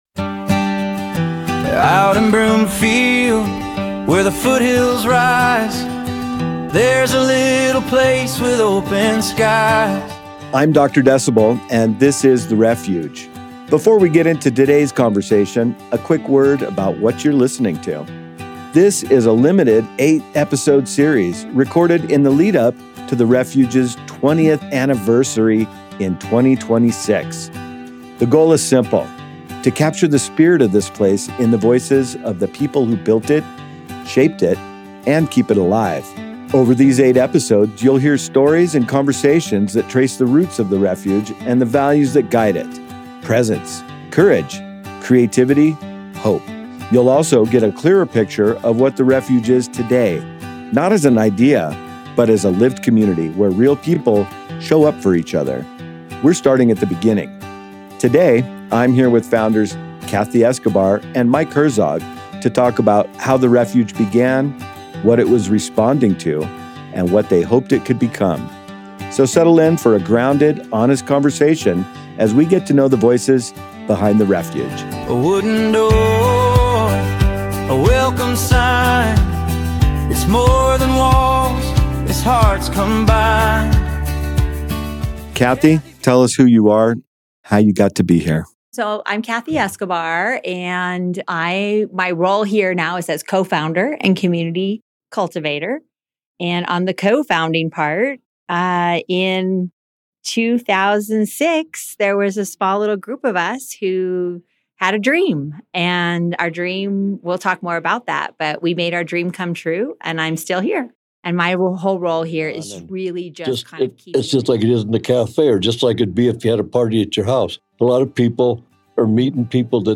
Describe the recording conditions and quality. These examples highlight the kind of clean, consistent, broadcast‑quality sound you can expect when we work together.